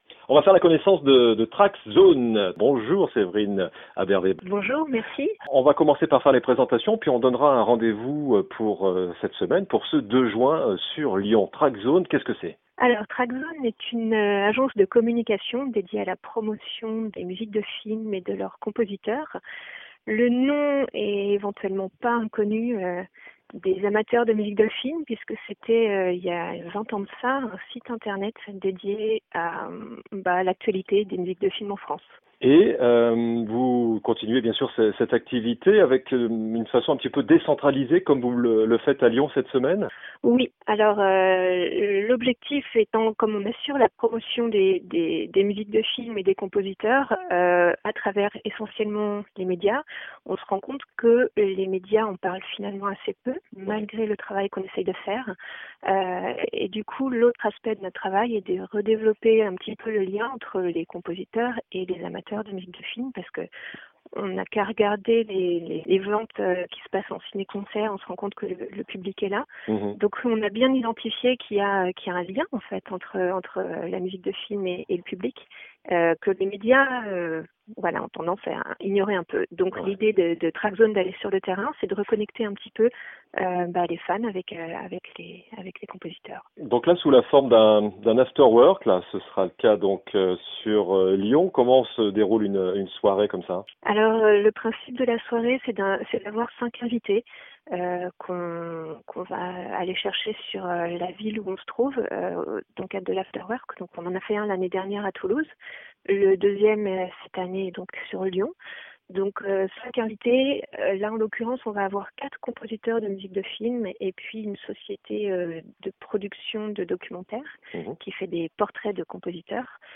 Podcasts cinéma : interviews | La Radio du Cinéma